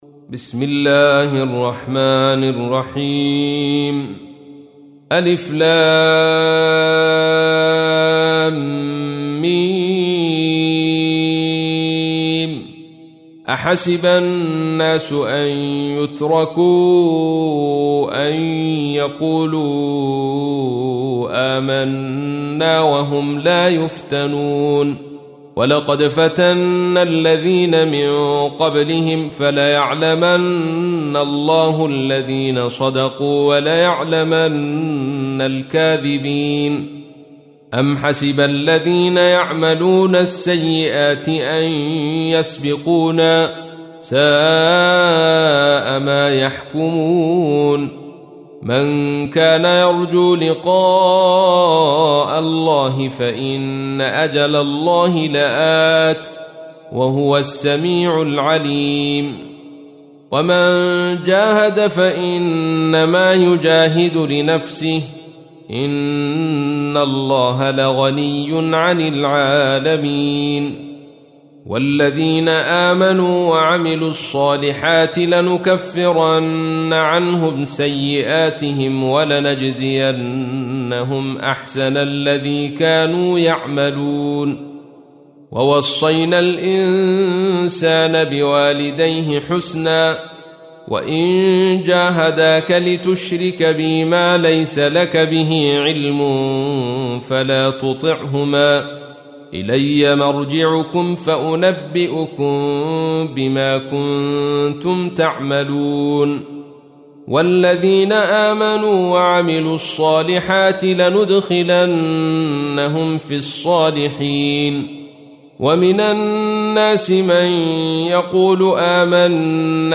تحميل : 29. سورة العنكبوت / القارئ عبد الرشيد صوفي / القرآن الكريم / موقع يا حسين